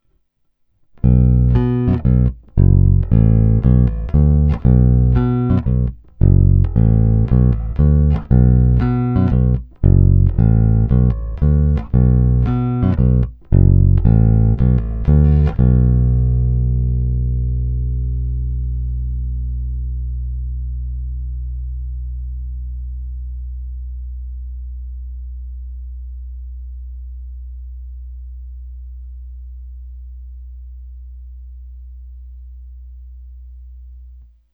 Zvukově je to opravdu typický Jazz Bass se vším všudy. Konkrétní, průrazný, zvonivý, na můj vkus možná mají snímače až moc ostrý zvuk, ale nic, čemu by nepomohla tónová clona. Není-li uvedeno jinak, následující nahrávky jsou provedeny rovnou do zvukové karty, jen normalizovány, jinak ponechány bez úprav.